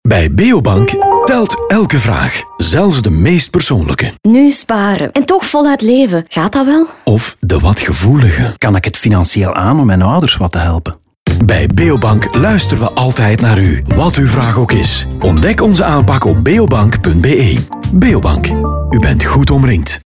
In de uitingen stellen klanten herkenbare levensvragen op verschillende sleutelmomenten in hun leven, waarmee ze iedereen uitnodigen hun vragen vrijuit te durven stellen.